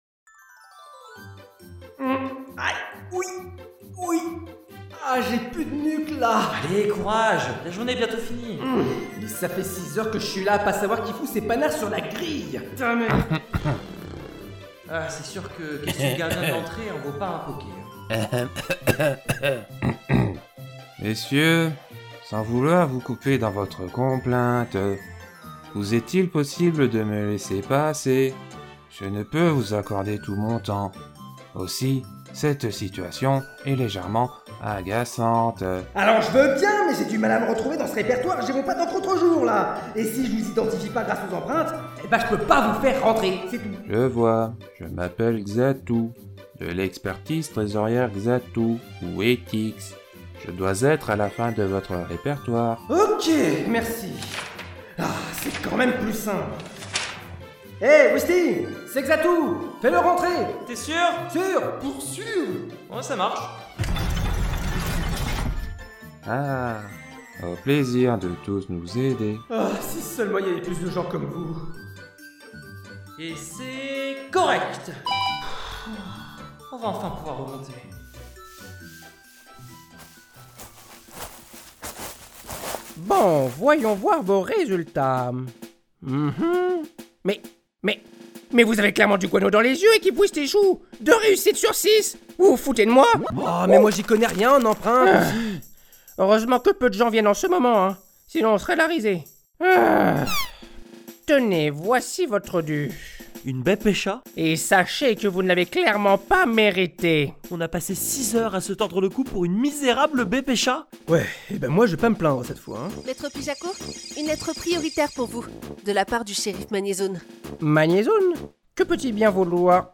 Si vous êtes ici c’est pour entendre une partie de JDR horrifique…